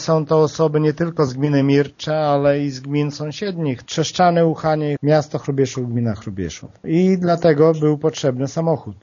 Wójt Lech Szopiński tłumaczy, że taki zakup był konieczny, ponieważ do tej pory placówka nie miała własnego pojazdu, a do przewozu podopiecznych z kilku różnych gmin musiała wynajmować zewnętrzną firmę: